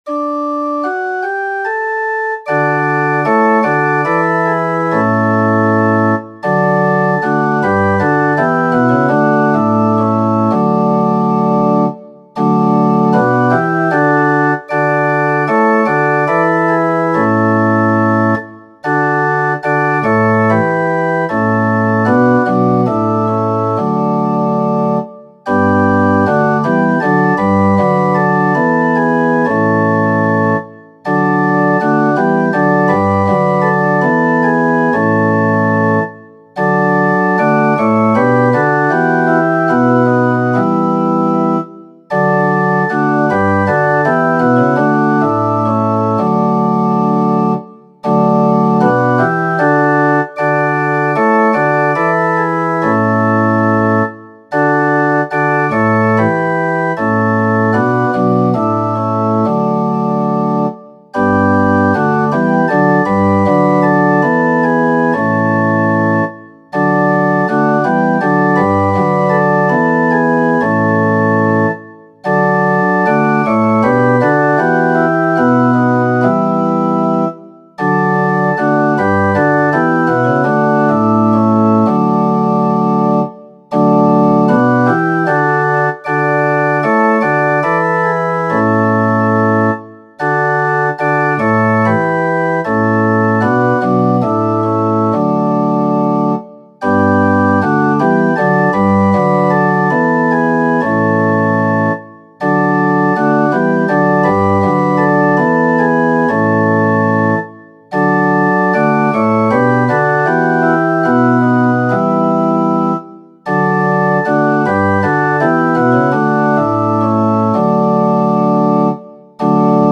CULTE DU 24 JANVIER 2021